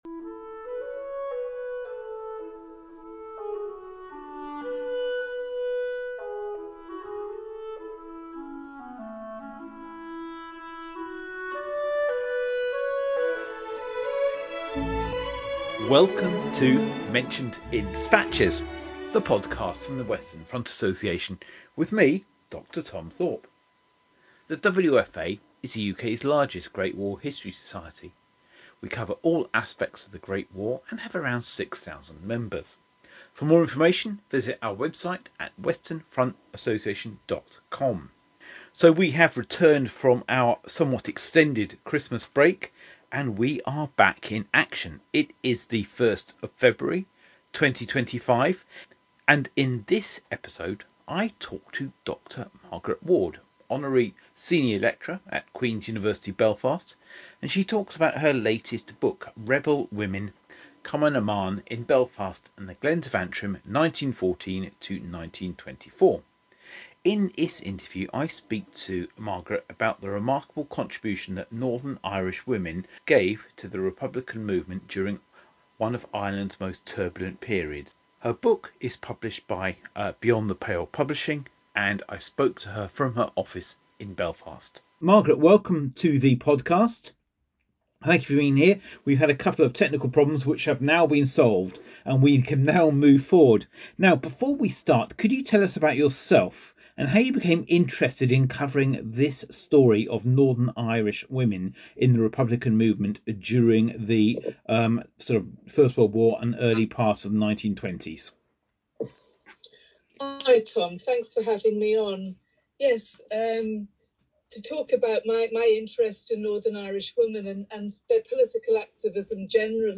In the interview